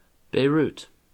Beirut (/bˈrt/
En-us-Beirut.oga.mp3